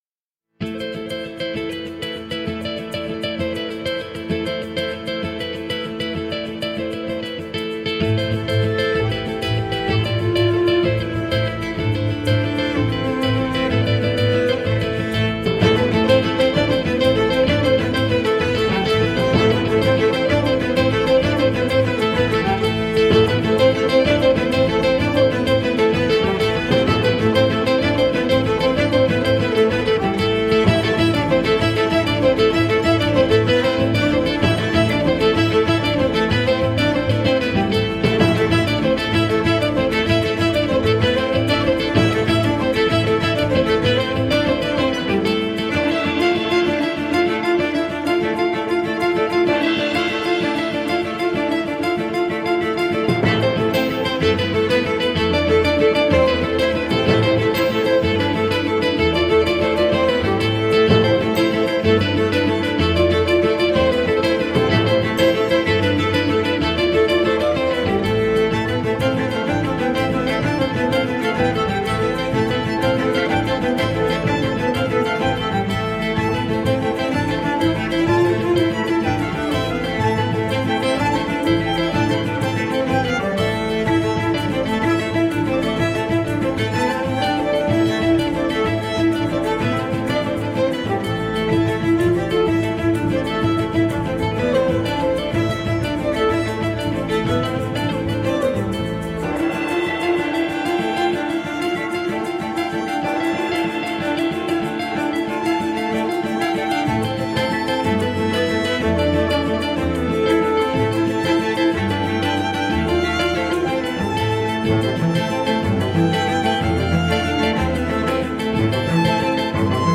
Early music meets global folk at the penguin café.